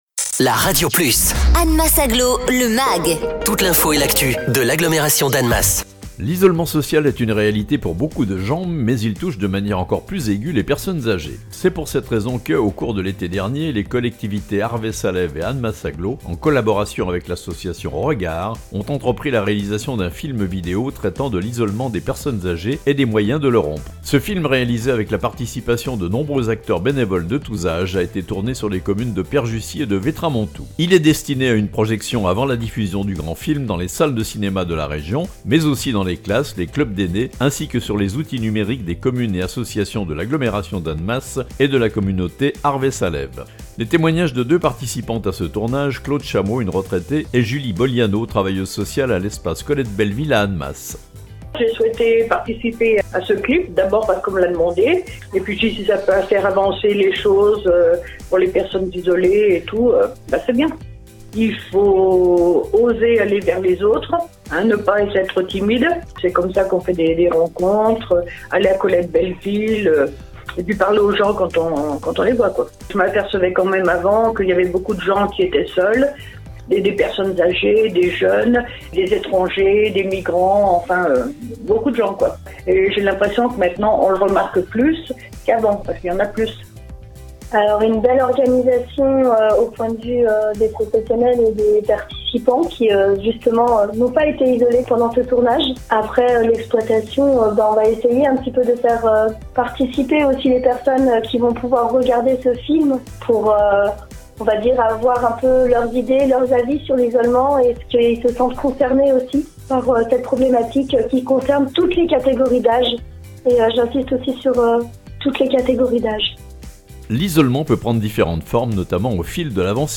Un clip vidéo a été tourné dans l'agglomération l'été dernier pour promouvoir la lutte contre l'isolement des personnes âgées. Deux personnes qui ont participé au tournage nous le présentent.